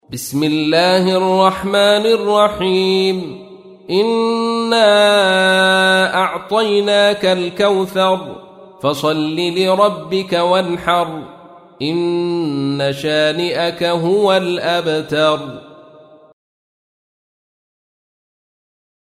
تحميل : 108. سورة الكوثر / القارئ عبد الرشيد صوفي / القرآن الكريم / موقع يا حسين